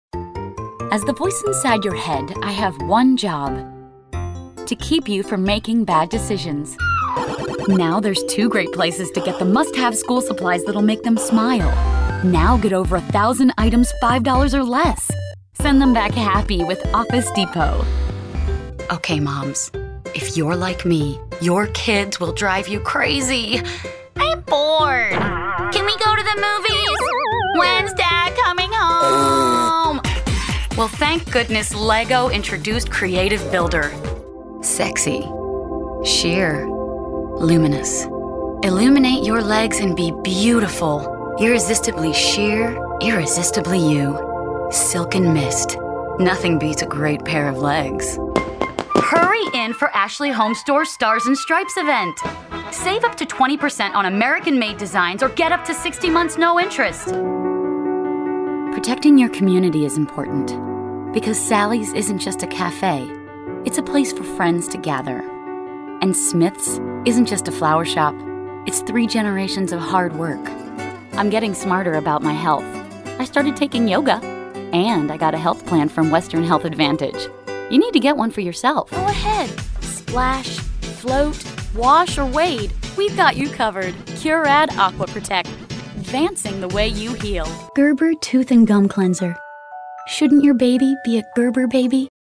Female Voice Over, Dan Wachs Talent Agency.
Believable, Caring, Conversational.
Commercial